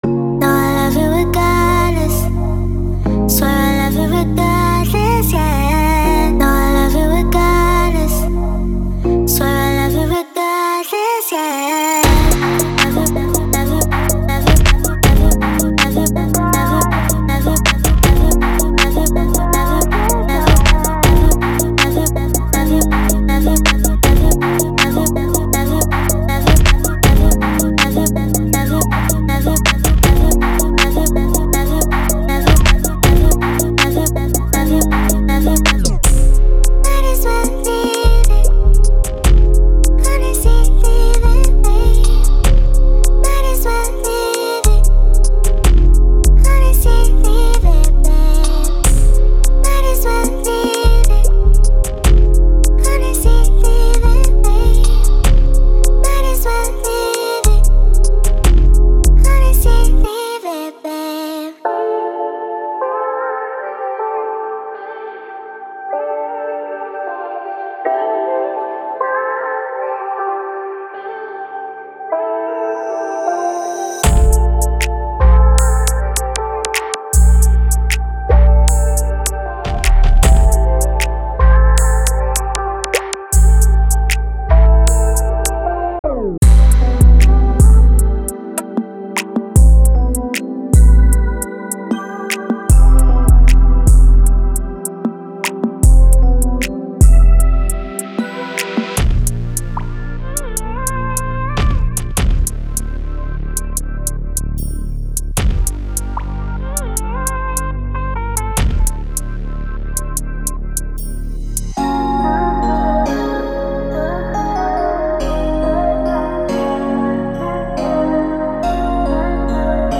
Genre:Soul and RnB